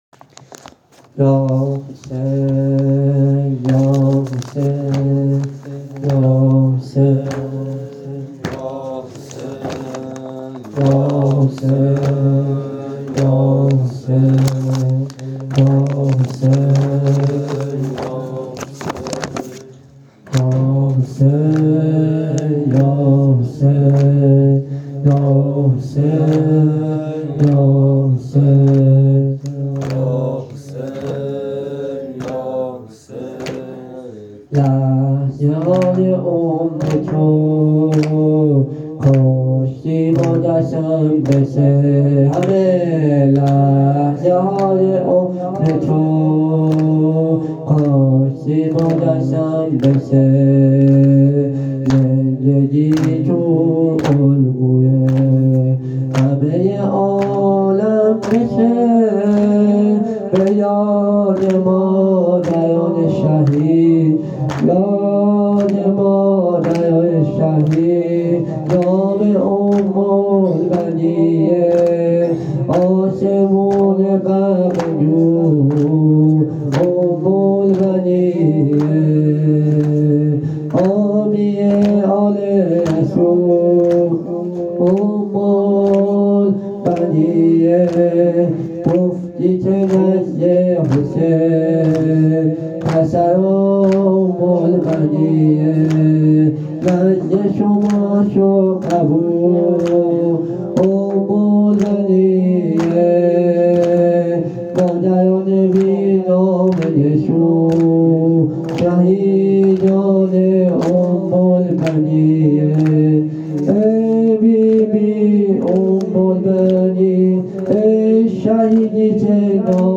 مداح اهل بیت
هیت روضه الزهرا تهران